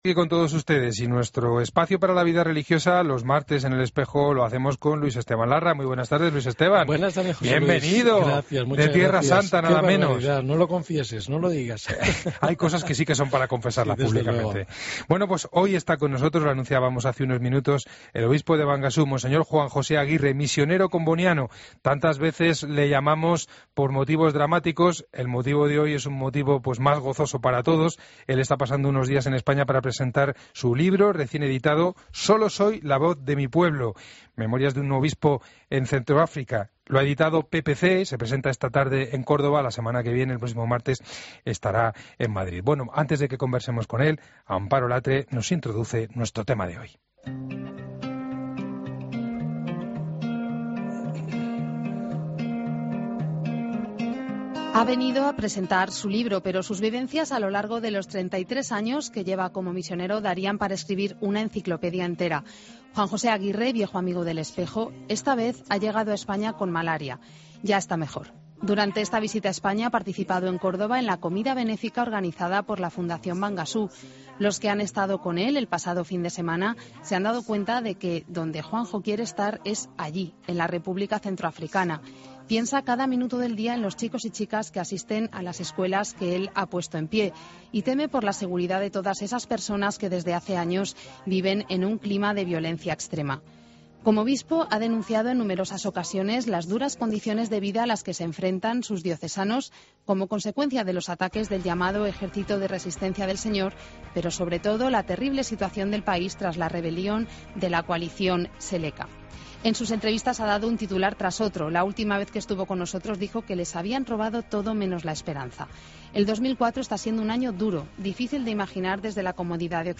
Escucha la entrevista completa al obispo de Bangassou en 'El Espejo' de COPE